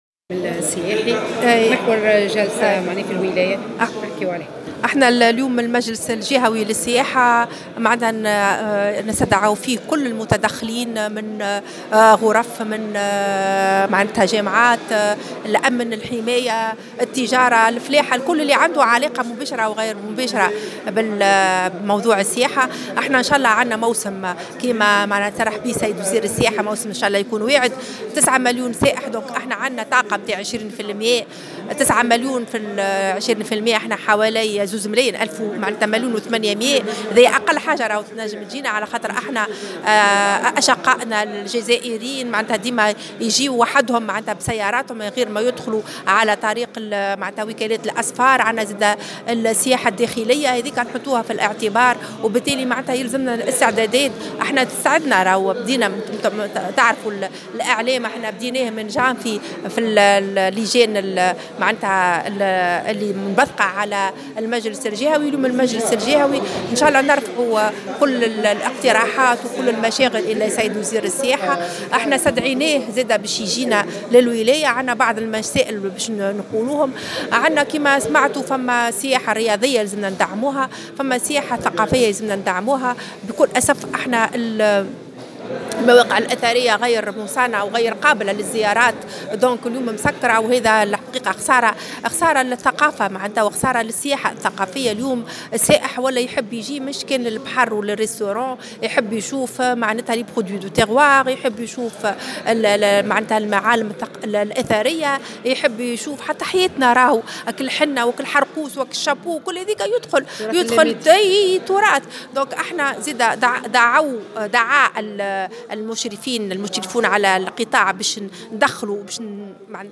على هامش انعقاد المجلس الجهوي للسياحة، استعدادات المتدخلين بالقطاع مشيرة إلى أهمية السياحة الثقافية والبيئية والتراث الذي تتمتع به جهة نابل.